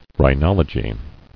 Rhi*nol"o*gist , n. One skilled in rhinology.